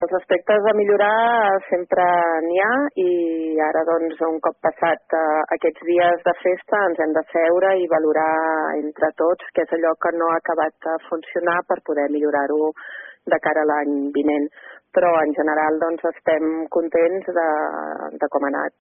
La regidora de Cultura ha destacat la bona acollida que han tingut les activitats noves del programa com les Tardes de Contes i les Nits Musicals, que s’han fet al parc del Castell.